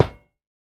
latest / assets / minecraft / sounds / block / iron / break4.ogg